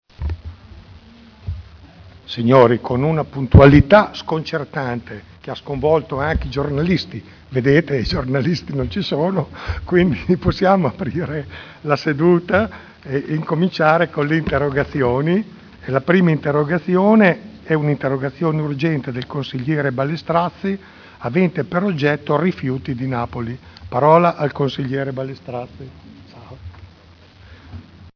Il Presidente Giancarlo Pellacani apre la seduta con le interrogazioni.